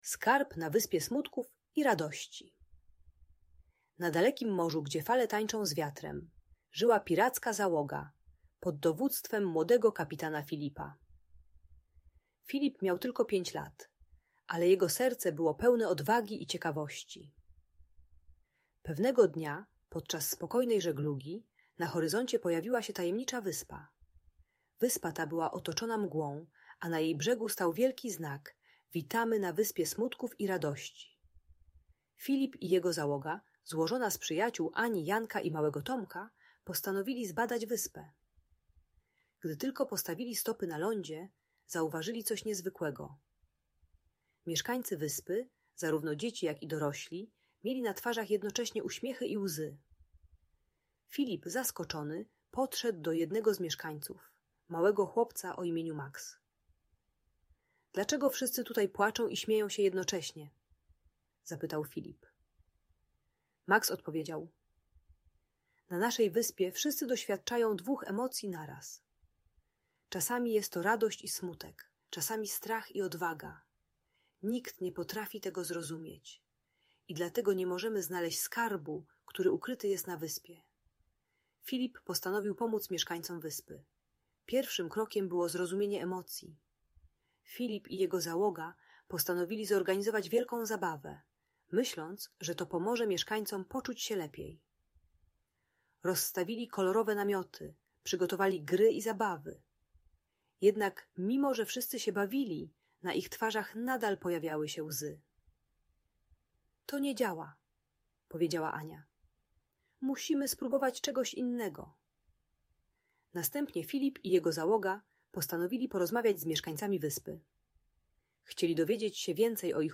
Skarb na Wyspie Smutków i Radości - Niepokojące zachowania | Audiobajka